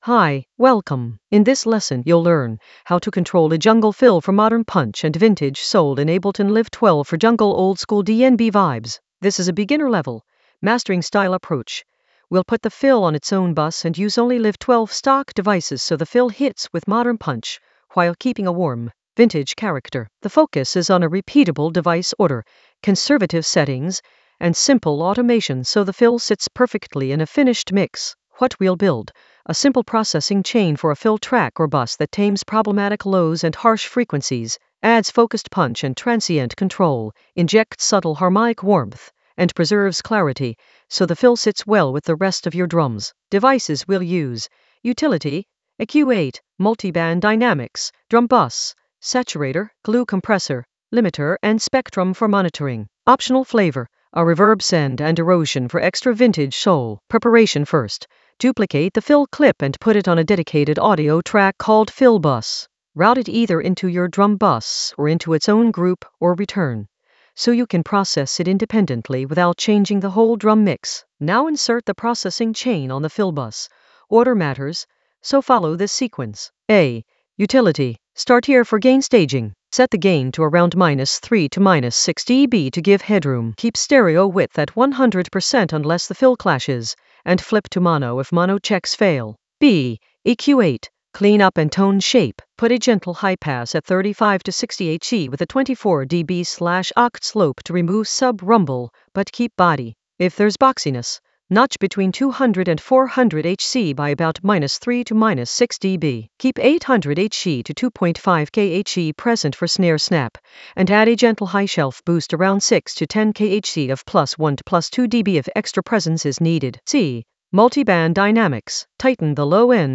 An AI-generated beginner Ableton lesson focused on Control a jungle fill for modern punch and vintage soul in Ableton Live 12 for jungle oldskool DnB vibes in the Mastering area of drum and bass production.
Narrated lesson audio
The voice track includes the tutorial plus extra teacher commentary.